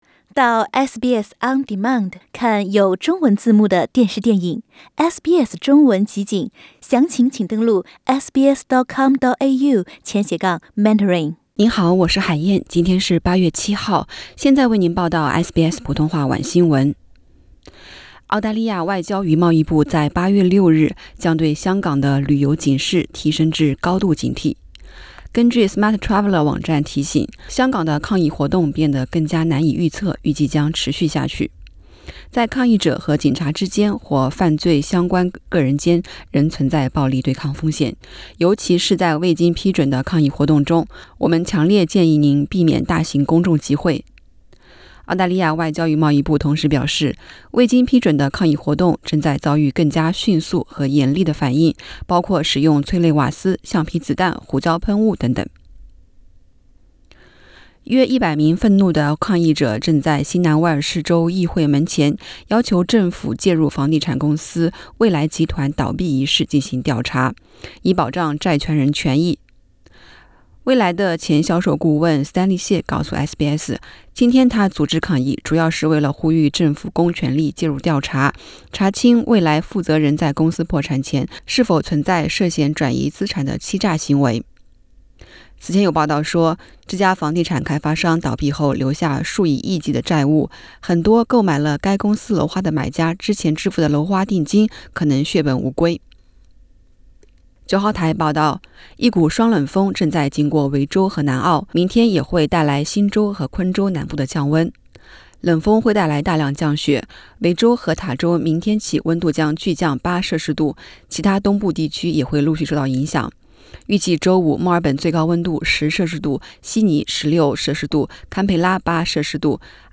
SBS晚新闻（8月7日）